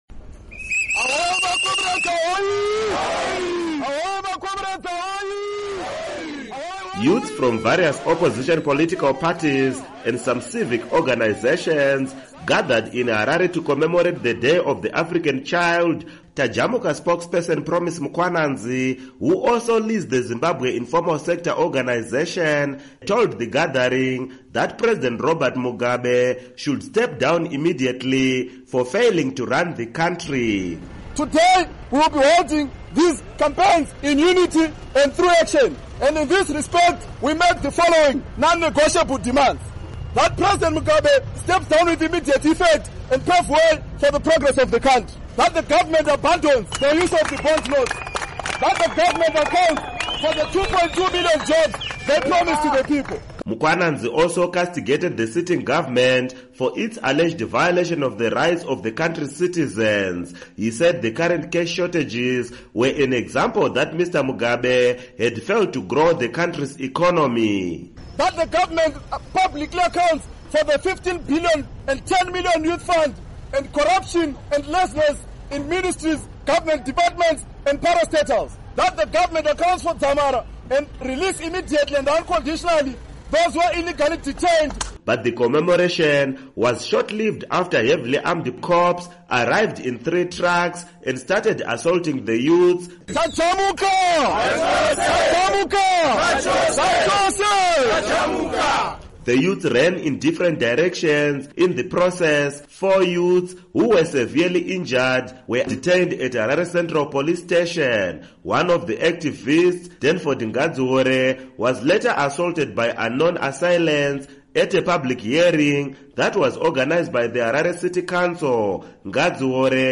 Report on Public Protests